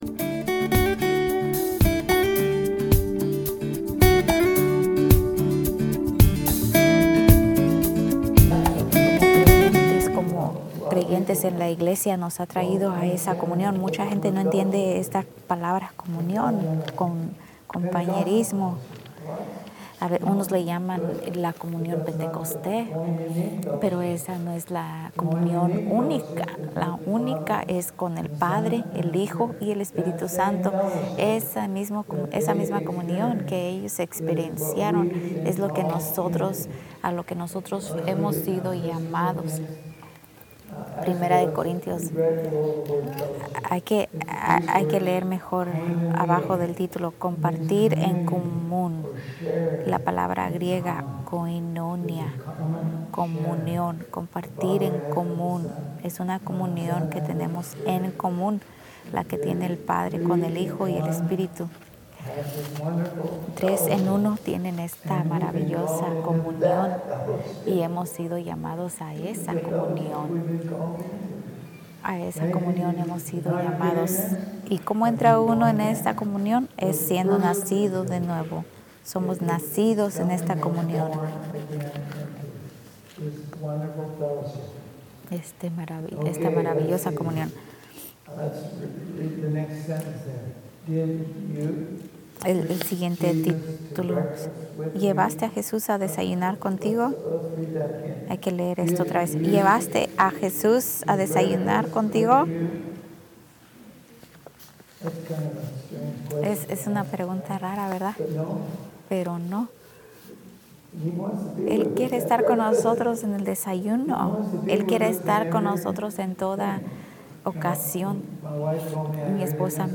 Sermones en Español